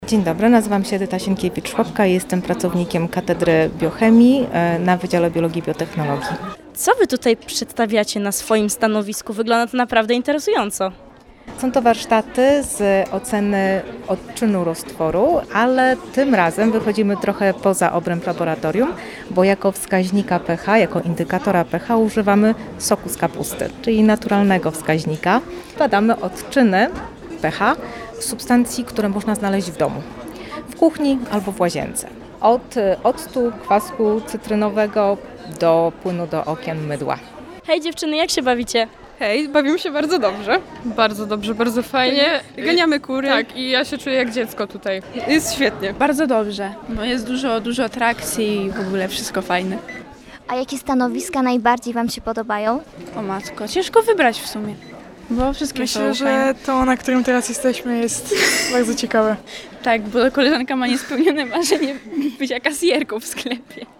Tegoroczną edycję Nocy Biologów w Kortowie zamknął koncert Studia Wokalnego UWM.